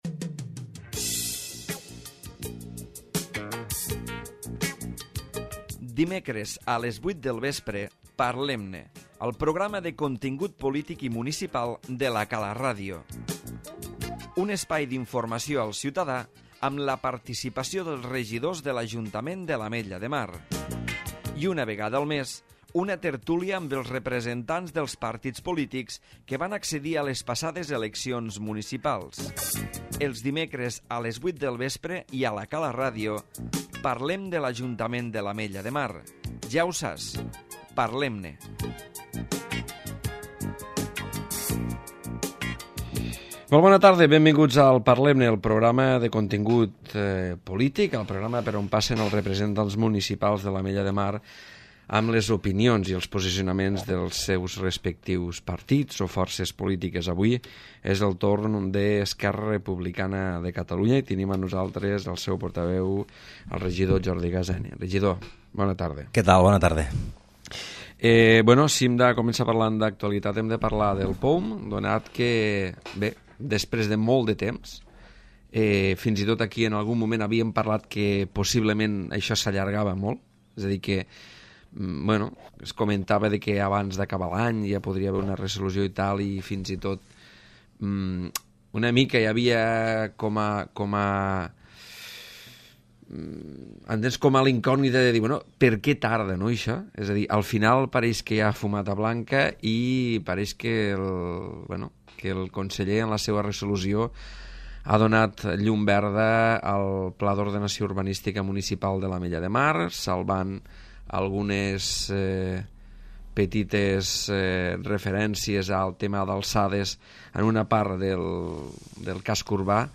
Mai, 2010 a les 21:00 per admin a Parlem-ne 2508 reproduccions Jordi Gaseni, portaveu del Grup Municipal d'ERC a l'Ametlla de Mar ha intervingut avui en el programa Parlem-ne, i ha donat la seva opinió respecte als principals temes d'actualitat municipal, tals com la resolució del conseller respecte a la modificació del POUM, la liquidació de l'exercici econòmic de 2009 o l'adjudicació de la residència per a la gent gran.